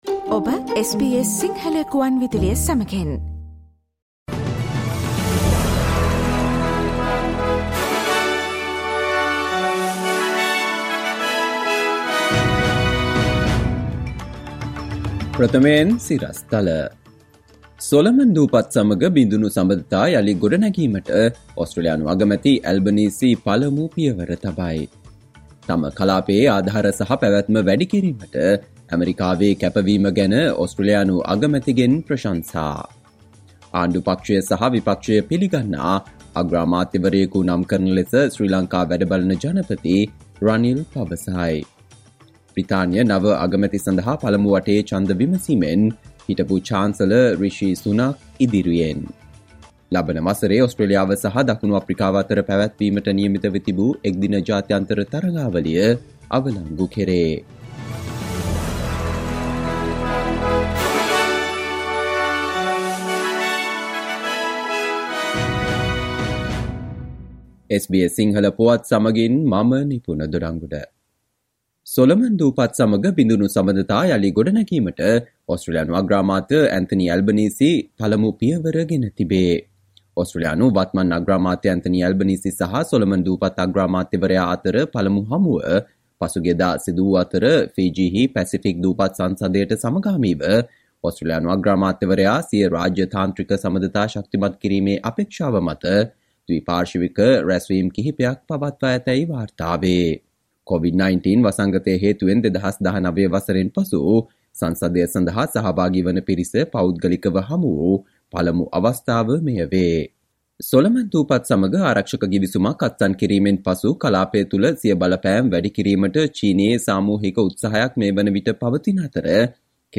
සවන්දෙන්න 2022 ජූලි 14 වන බ්‍රහස්පතින්දා SBS සිංහල ගුවන්විදුලියේ ප්‍රවෘත්ති ප්‍රකාශයට...